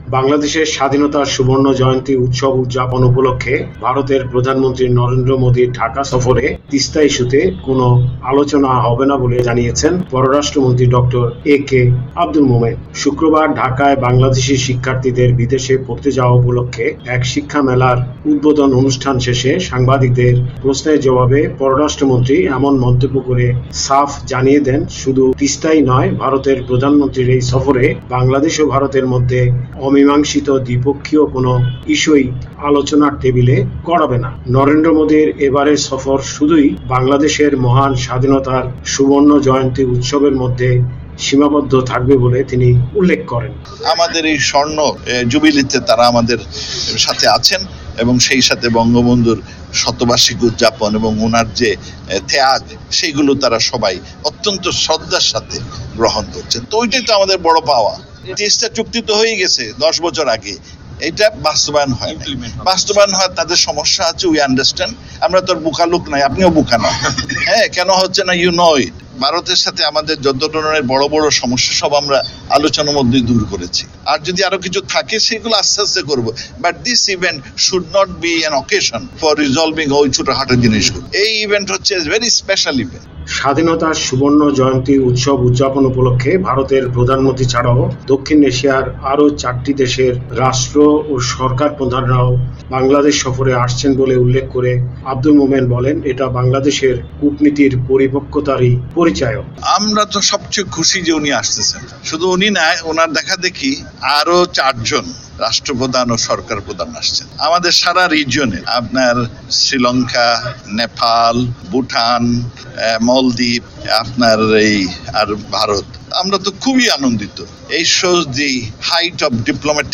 শুক্রবার ঢাকায় বাংলাদেশি শিক্ষার্থীদের বিদেশে পড়তে যাওয়া উপলক্ষে এক শিক্ষা মেলার উদ্বোধন অনুষ্ঠান শেষে সাংবাদিকদের প্রশ্নের জবাবে পররাষ্ট্রমন্ত্রী এমন মন্তব্য করে সাফ জানিয়ে দেন শুধু তিস্তাই নয় ভারতের প্রধানমন্ত্রীর এই সফরে বাংলাদেশ ও ভারতের মধ্যে অমীমাংসিত দ্বিপক্ষীয় কোনও ইস্যুই আলোচনার টেবিলে গড়াবে না।